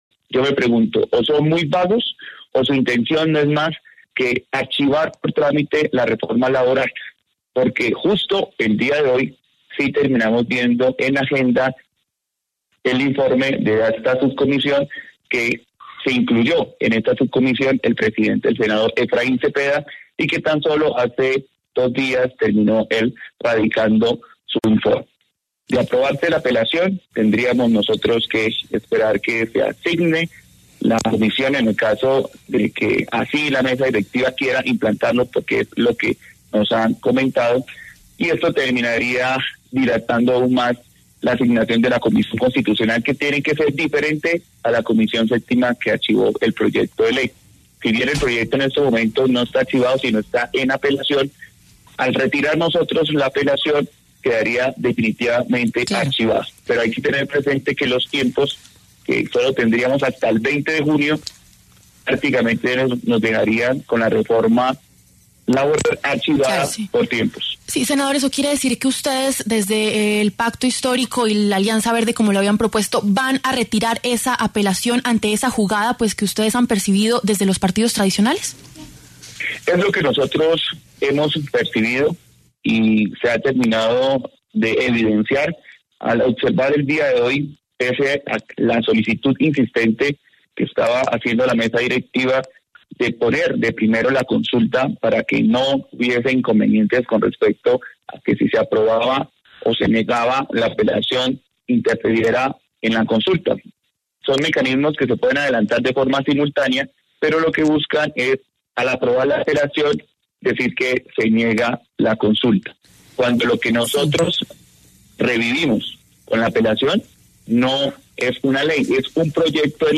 El senador Fabián Díaz pasó por los micrófonos de La W y aseguró que él tiene la potestad de retirar la apelación que busca revivir la reforma laboral en el Congreso, para frenar una “jugada” de los sectores tradicionales.